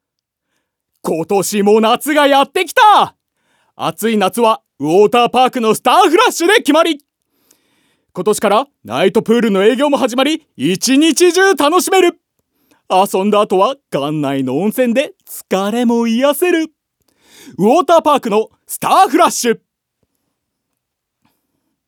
ナレーション1